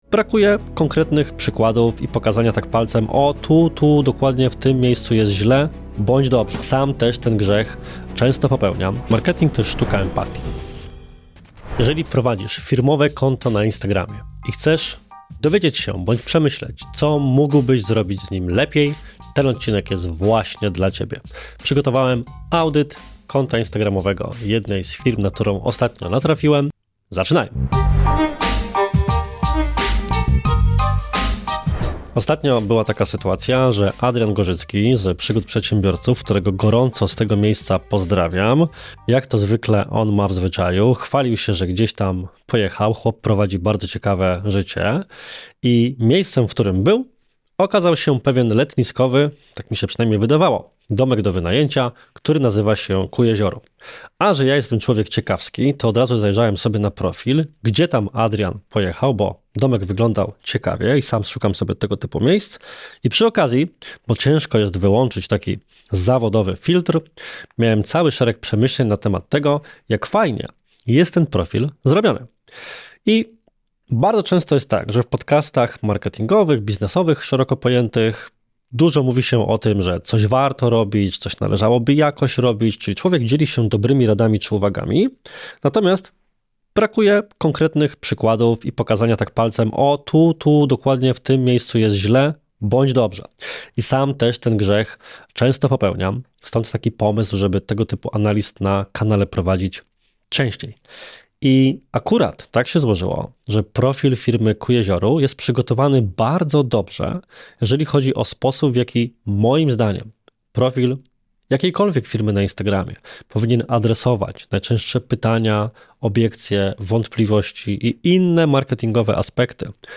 Podcast Konkretnie o marketingu i sprzedaży - zgodnie z nazwą - dostarczy Ci wskazówek, dzięki którym zwiększysz skuteczność działań marketingowych i sprzedażowych w swojej firmie. Prowadzący i zaproszeni goście dzielą się własnym doświadczeniem i wypracowanymi studiami przypadku.